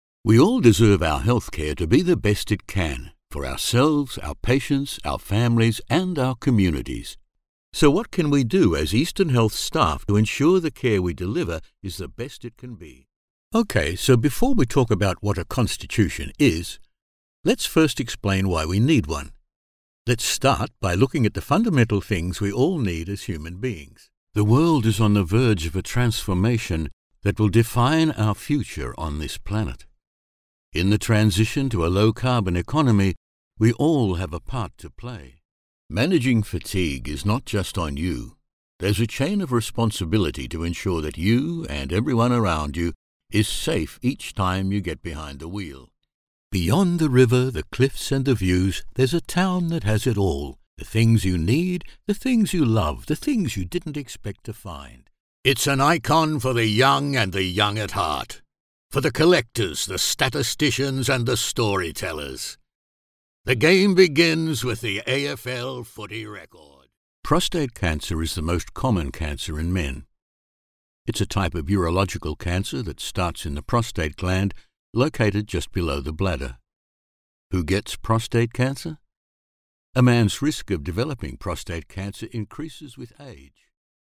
English (Neutral - Mid Trans Atlantic)
Adult (30-50) | Older Sound (50+)